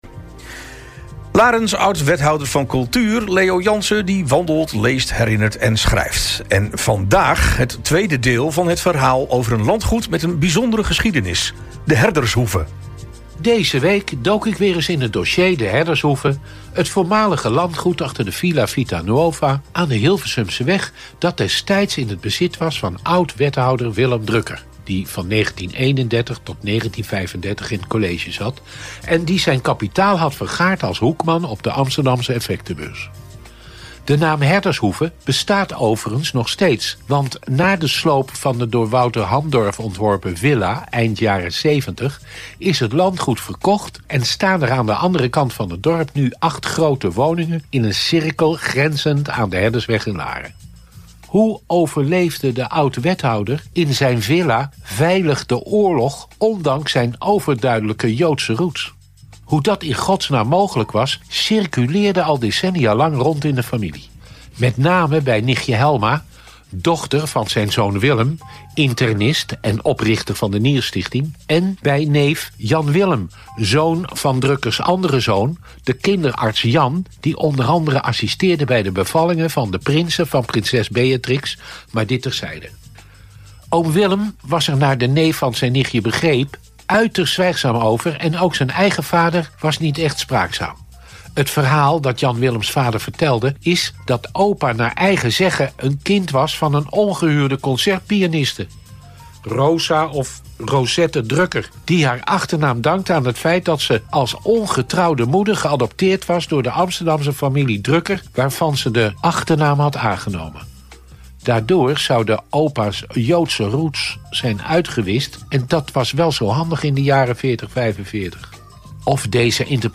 All Inclusive - Column door Leo Janssen: De Herdershoeve (deel 2)
Larens oud-wethouder van Cultuur - Leo Janssen - wandelt, leest, herinnert en schrijft. Vandaag het tweede deel van het verhaal over een landgoed met een bijzondere geschiedenis: De Herdershoeve.